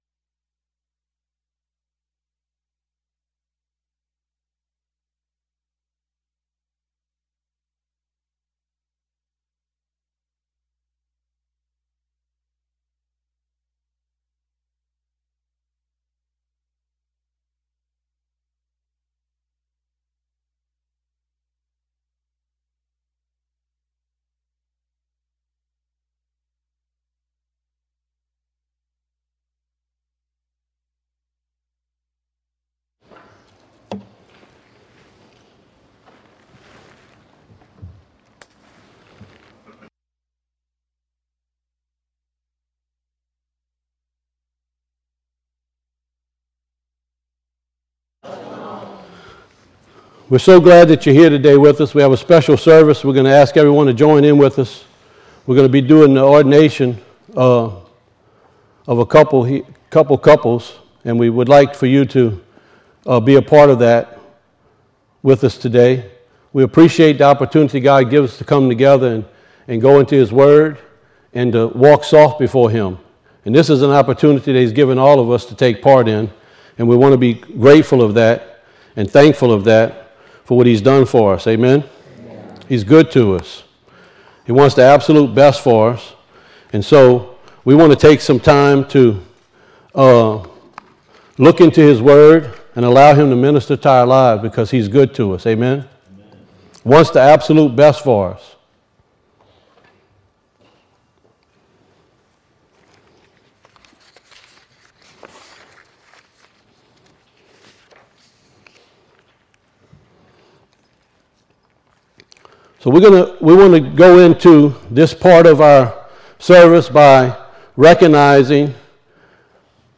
This is the recording of just the message shared before the ordination.